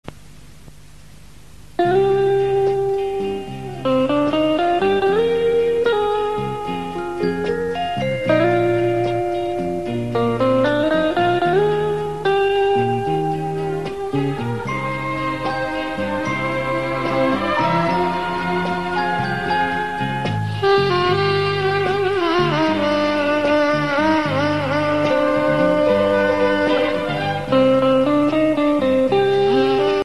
Гитара и саксофон.